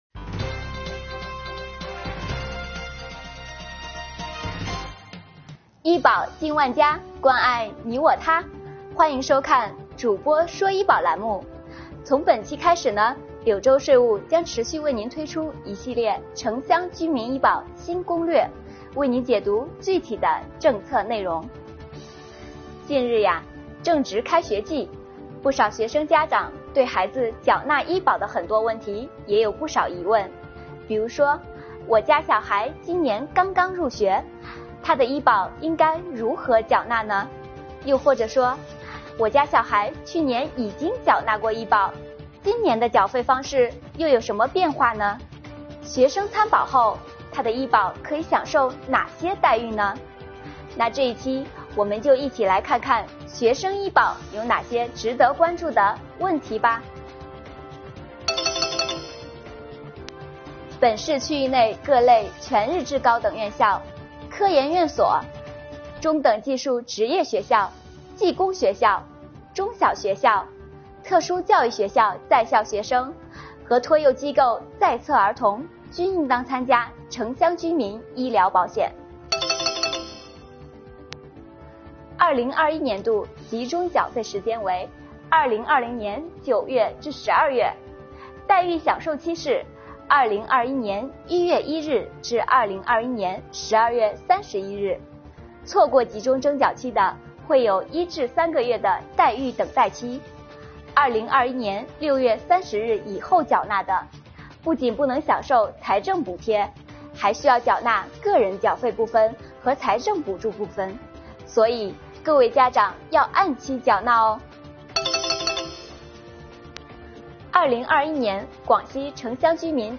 主播请您看攻略！